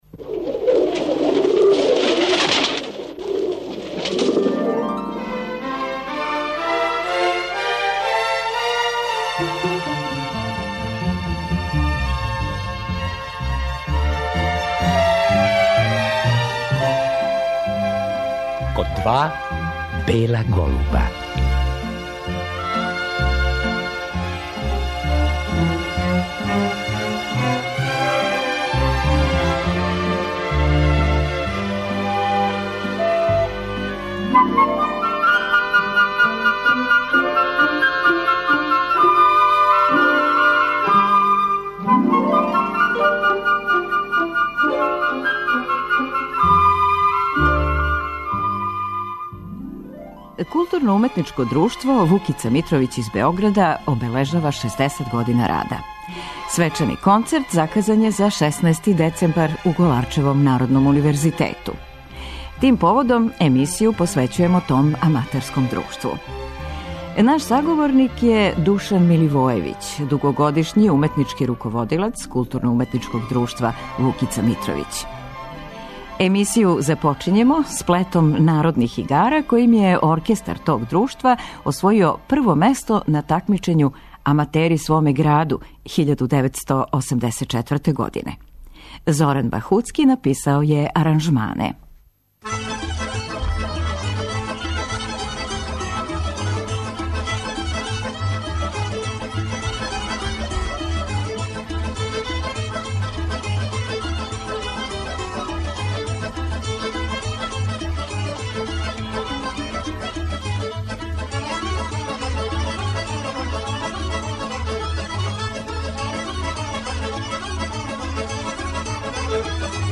Чућемо снимак концерта одржаног 1987. године у Београду.